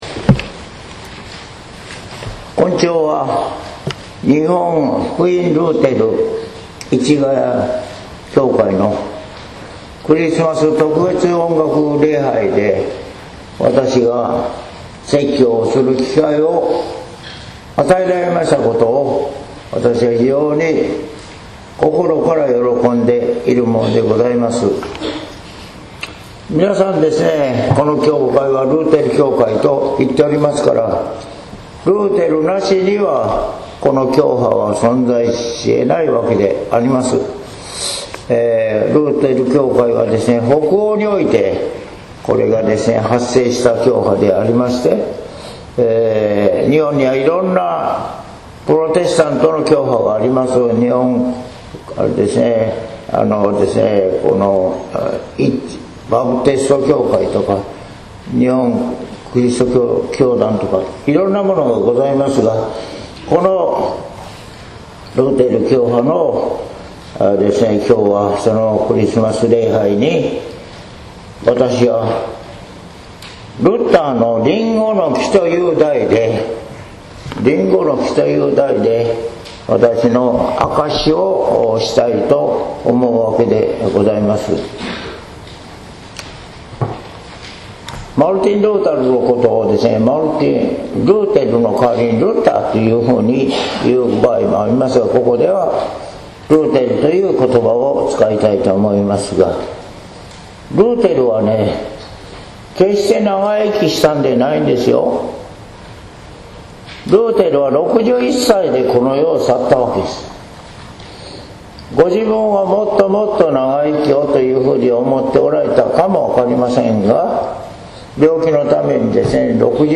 説教「ルターのりんごの木」（音声版）
日野原重明氏 マタイ１３：１～９ 待降節第２主日（2013年12月8日） ２２分